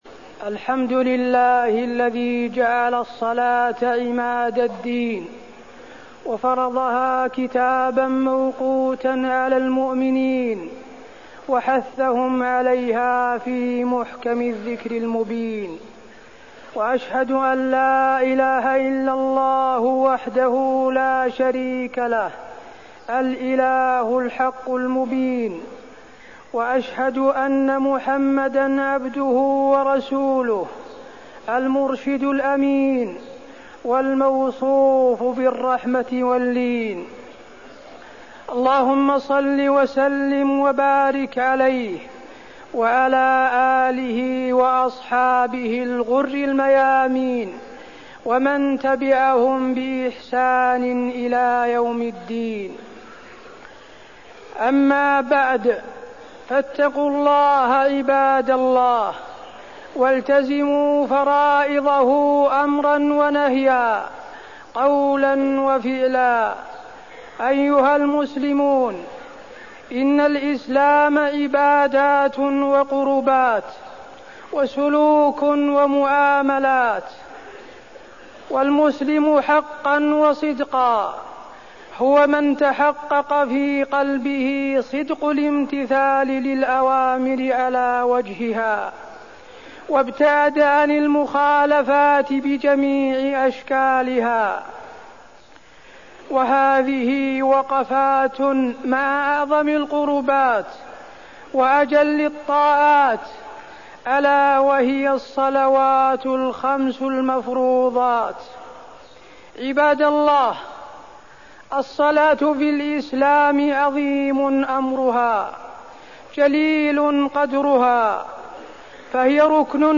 تاريخ النشر ٢٠ ذو الحجة ١٤١٨ هـ المكان: المسجد النبوي الشيخ: فضيلة الشيخ د. حسين بن عبدالعزيز آل الشيخ فضيلة الشيخ د. حسين بن عبدالعزيز آل الشيخ الصلاة وأهميتها